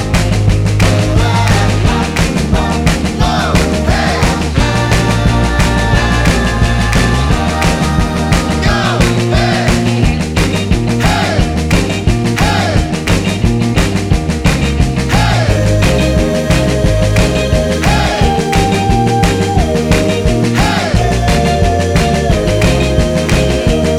no Backing Vocals Rock 'n' Roll 2:31 Buy £1.50